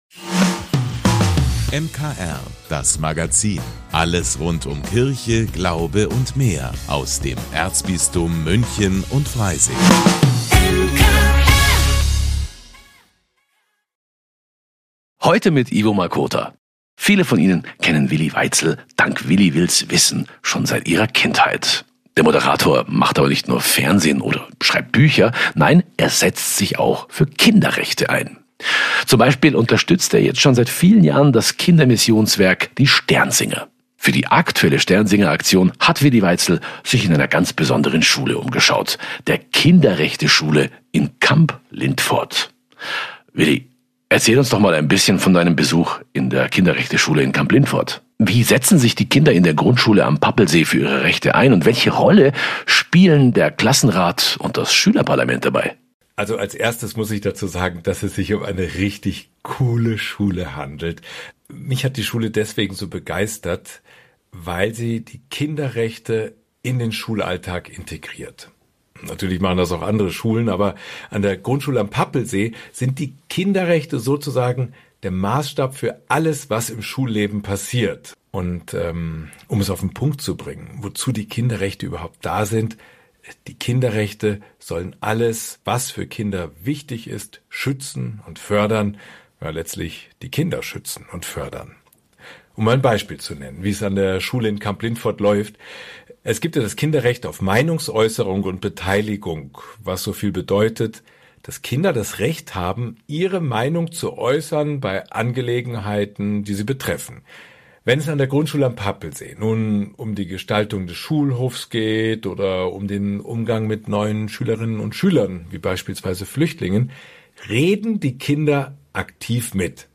- Willi Weitzel im Interview zur Sternsinger-Aktion, die der bekannte Moderator auch dieses Jahr wieder tatkräftig unterstützt.